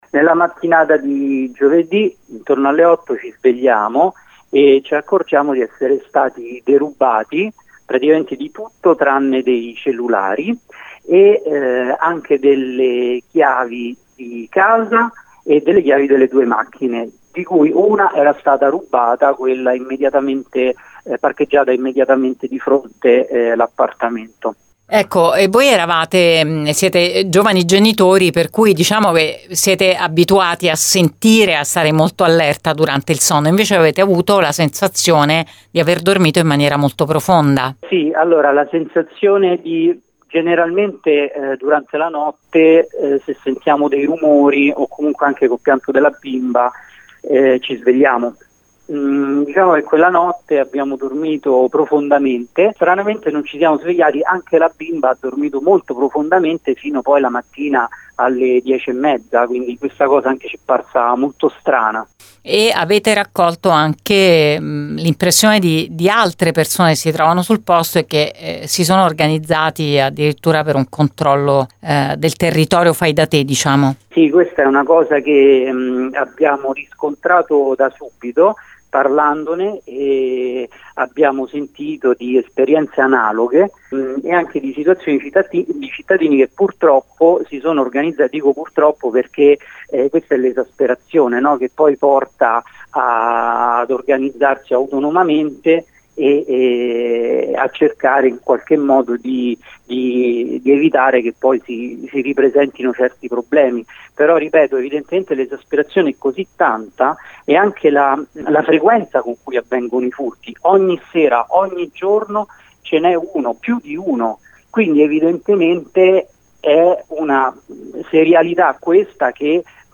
Il racconto a Gr Latina